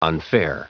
Prononciation du mot unfair en anglais (fichier audio)
Prononciation du mot : unfair